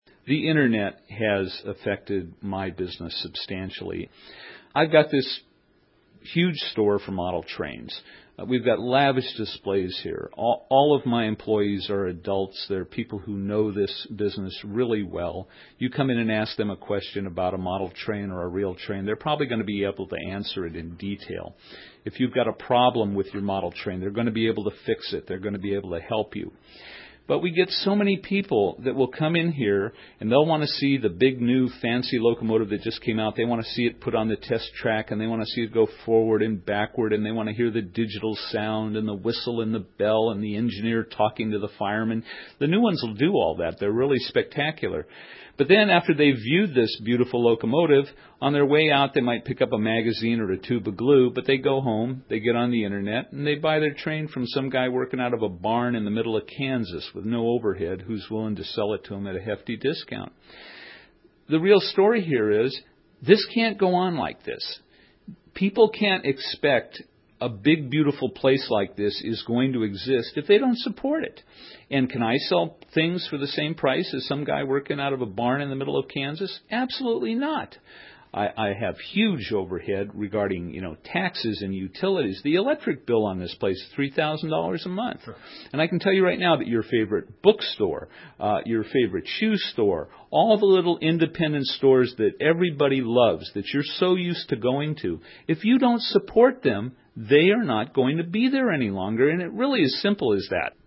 recent interview.